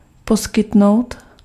Ääntäminen
IPA: /dis.pɑ̃.se/